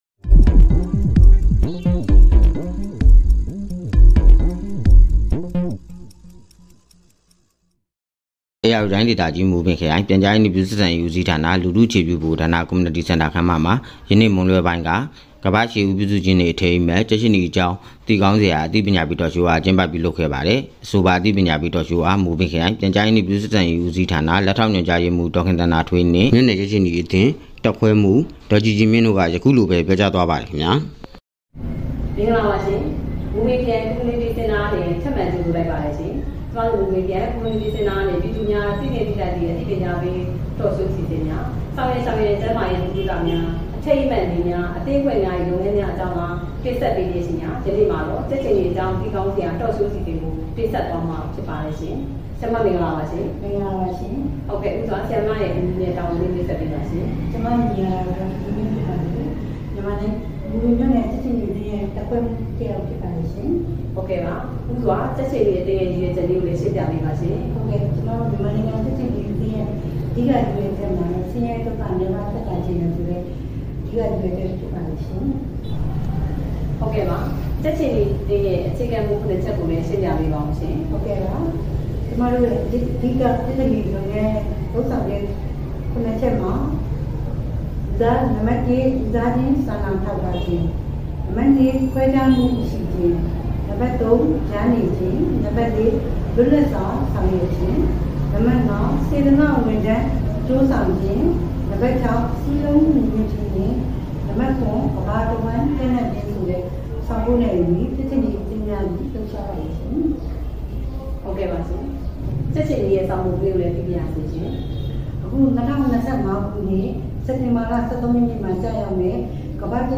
Community Centre ခန်းမတွင် ကြက်ခြေနီအကြောင်းသိကောင်းစရာ အသိပညာပေး Talk Show ပြုလုပ် မအူပင် စက်တင်ဘာ ၁၄ ပေးပို့သူ